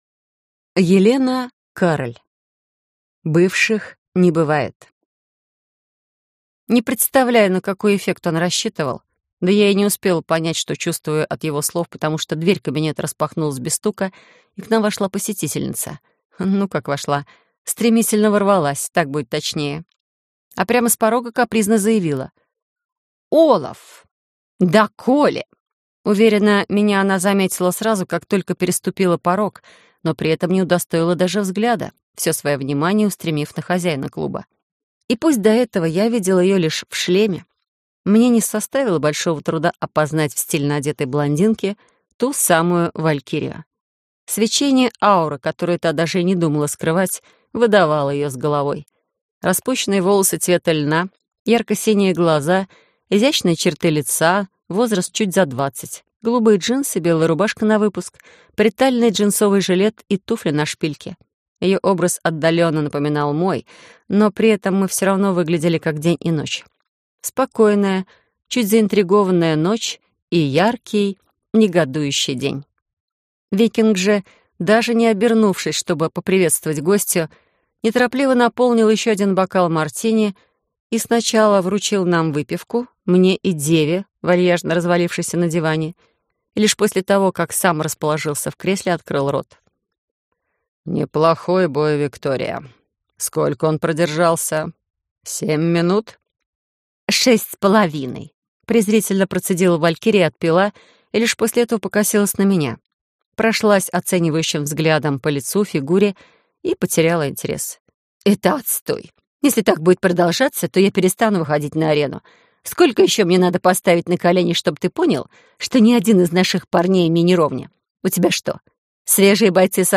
Аудиокнига Бывших не бывает - купить, скачать и слушать онлайн | КнигоПоиск